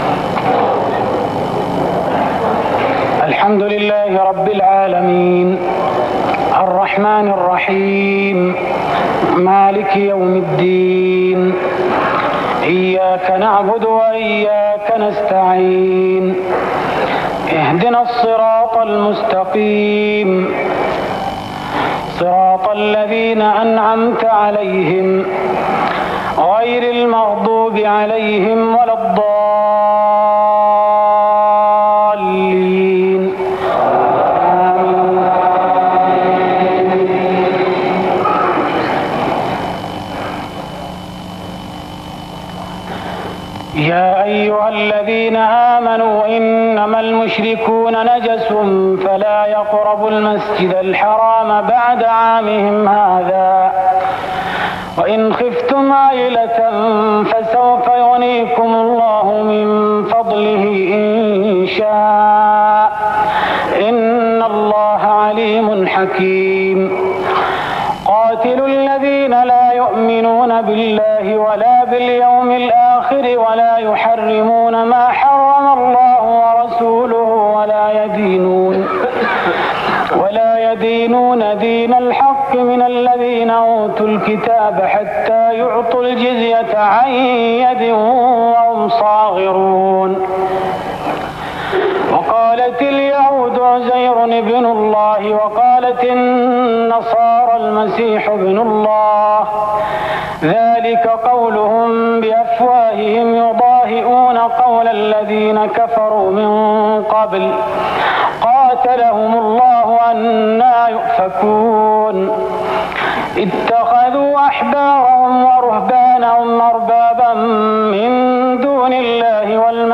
تهجد ليلة 27 رمضان 1417هـ من سورة التوبة (28-110) Tahajjud 27th night Ramadan 1417H from Surah At-Tawba > تراويح الحرم النبوي عام 1417 🕌 > التراويح - تلاوات الحرمين